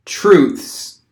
/tɹuθs/, fifths (/fɪfθs/ or /fɪθs/), sixths (
En-US_truths.ogg.mp3